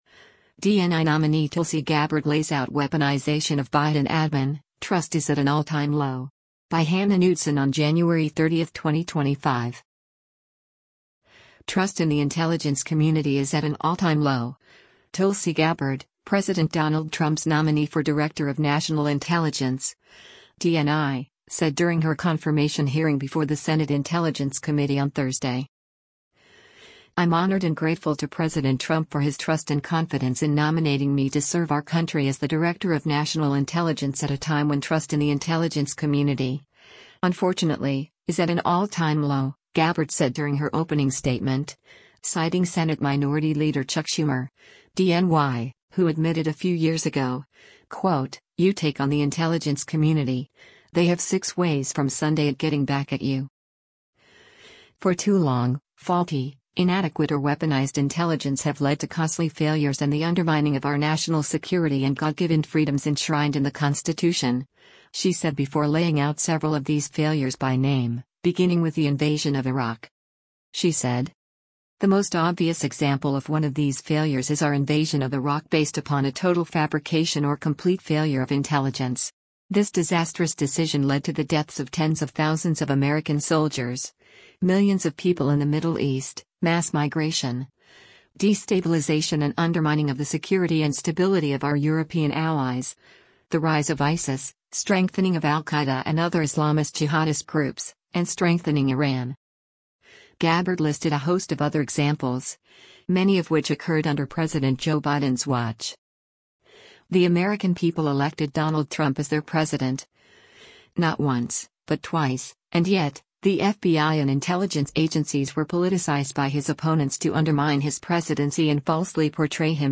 Tulsi Gabbard testifies during a Senate Intelligence Committee hearing on her nomination t
Trust in the intelligence community is at an “all-time low,” Tulsi Gabbard, President Donald Trump’s nominee for Director of National Intelligence (DNI), said during her confirmation hearing before the Senate Intelligence Committee on Thursday.